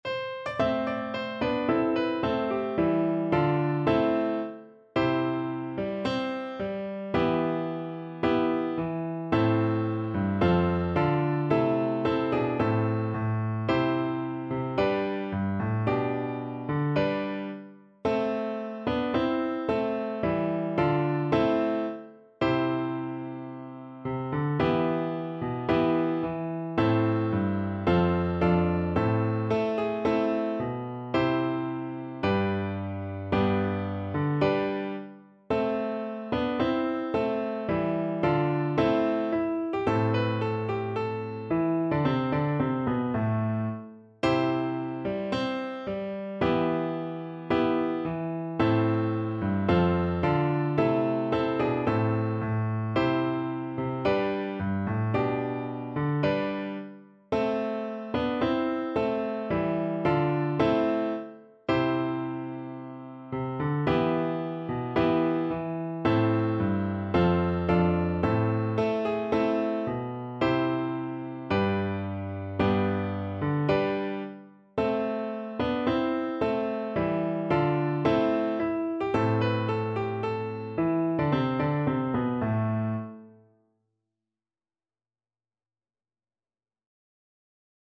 ~ = 110 Allegro (View more music marked Allegro)
Traditional (View more Traditional Violin Music)